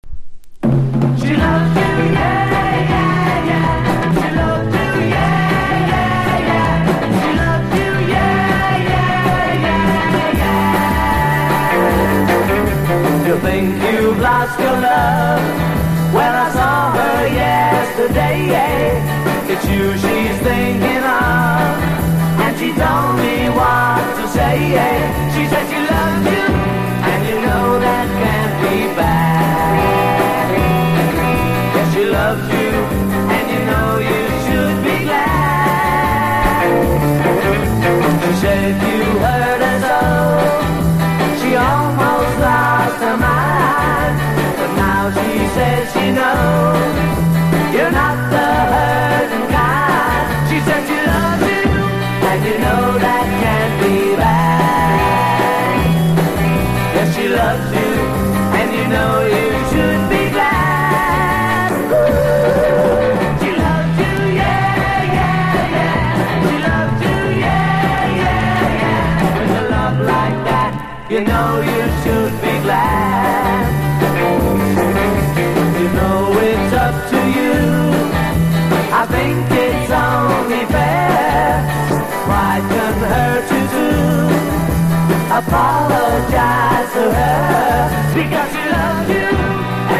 1. 60'S ROCK >